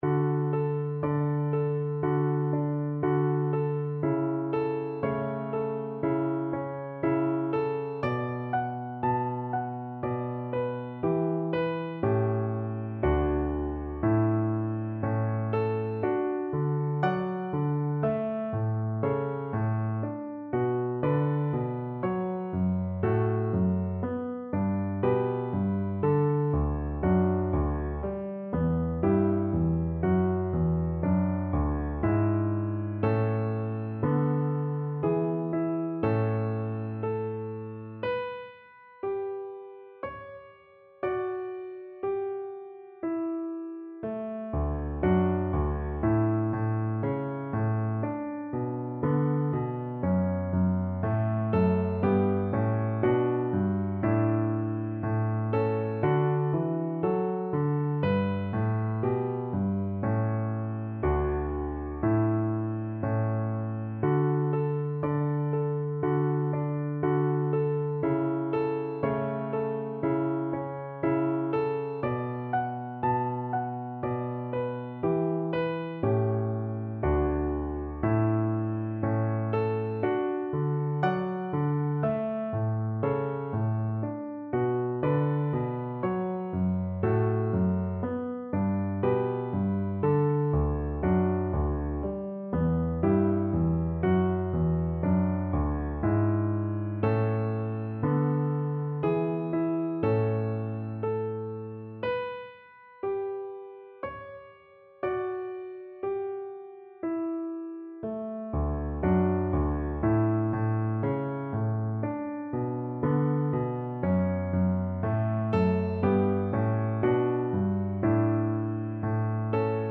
No parts available for this pieces as it is for solo piano.
Adagio = c.60
4/4 (View more 4/4 Music)
Classical (View more Classical Piano Music)